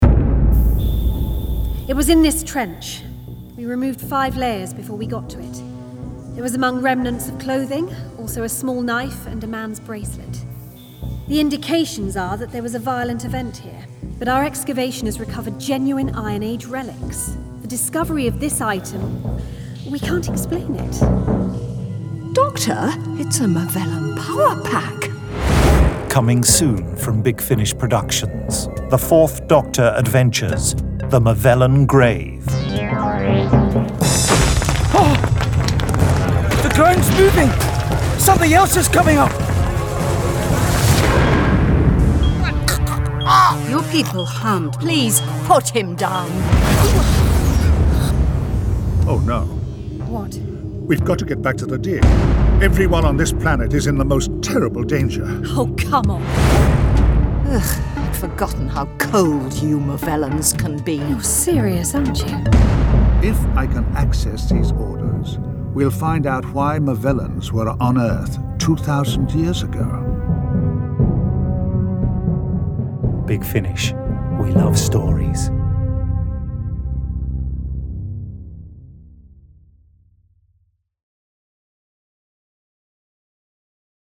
Award-winning, full-cast original audio dramas from the worlds of Doctor Who